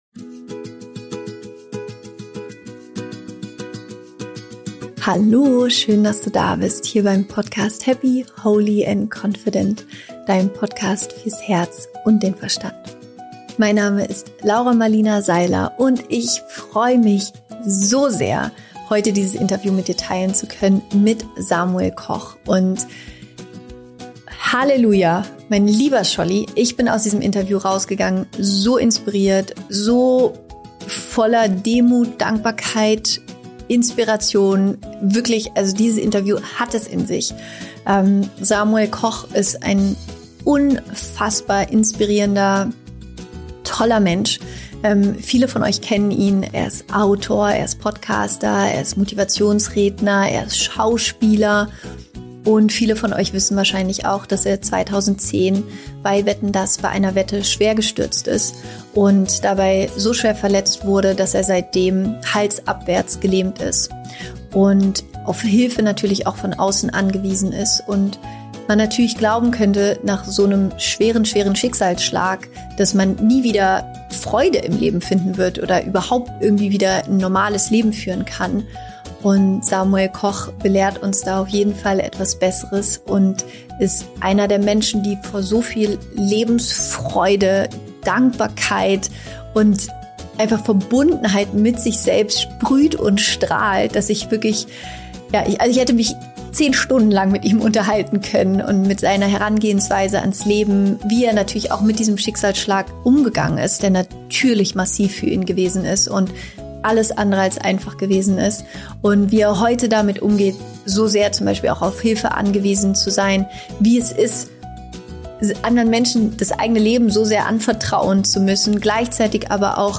Warum du dem Leben vertrauen darfst – Interview mit Samuel Koch